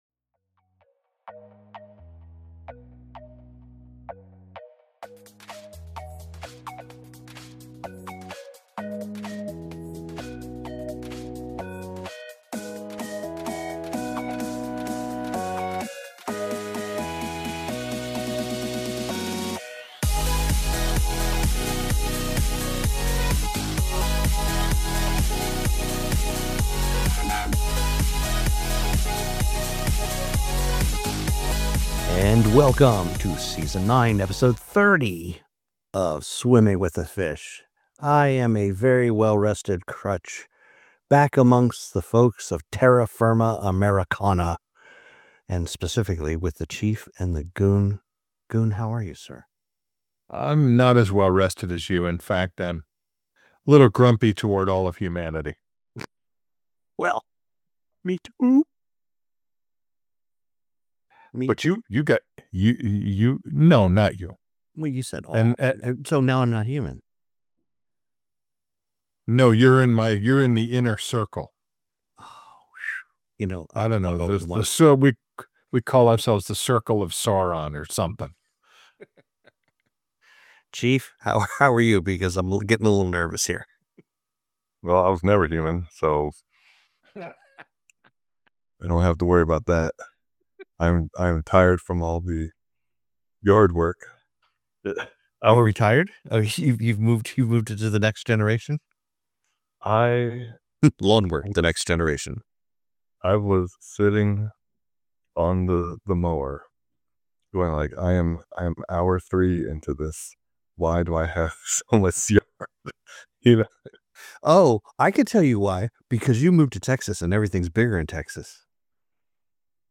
In this solo episode, I talk about the quiet cost of staying stuck in outdated strategies -…